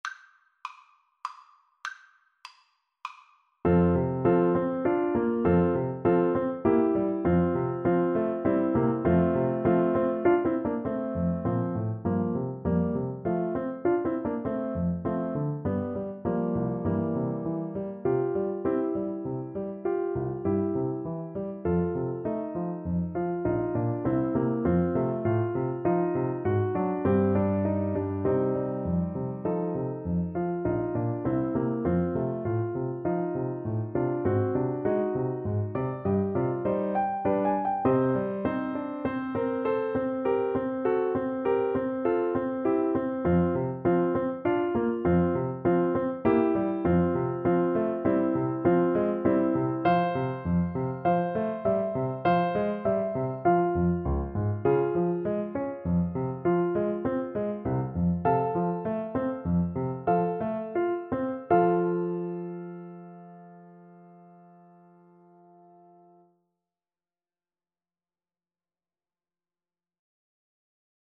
Play (or use space bar on your keyboard) Pause Music Playalong - Piano Accompaniment Playalong Band Accompaniment not yet available reset tempo print settings full screen
3/4 (View more 3/4 Music)
~ = 100 Allegretto grazioso (quasi Andantino) (View more music marked Andantino)
Classical (View more Classical French Horn Music)